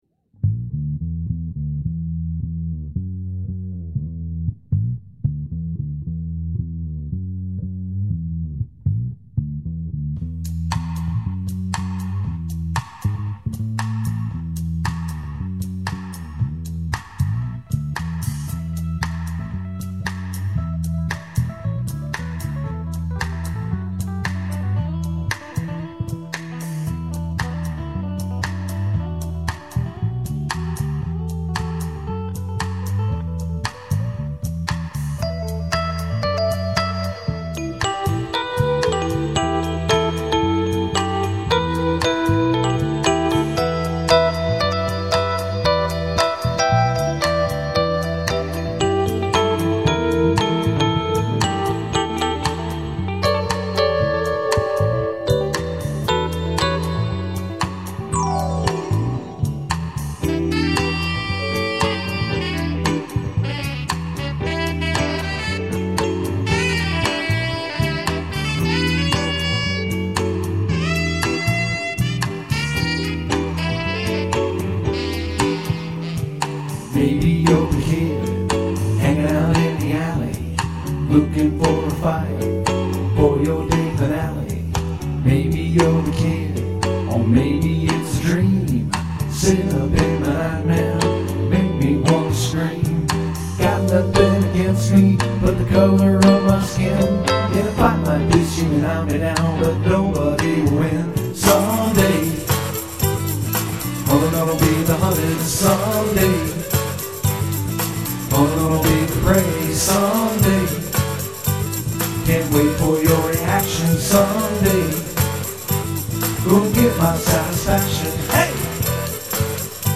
Keys, Vocals
Guitar, Vocals
Bass, Vocals
Drums
Congas, Mandolin Auxiliary Percussion Vocals